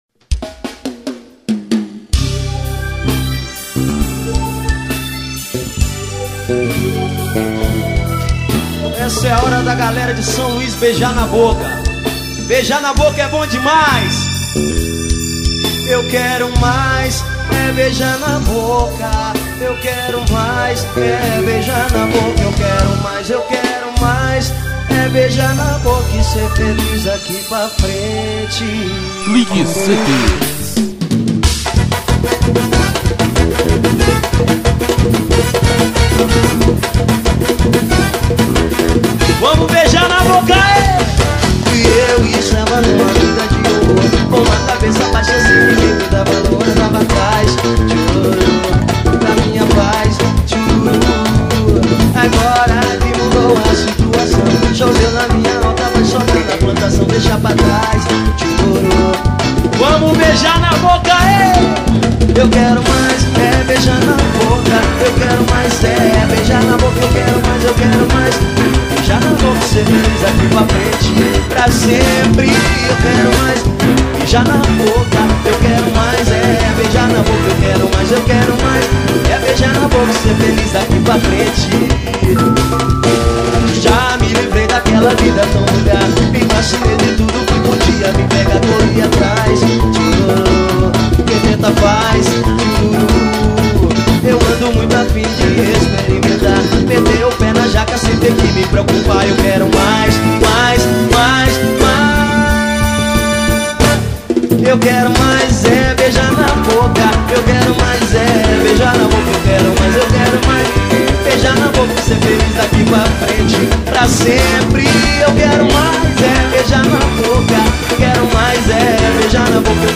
Axé.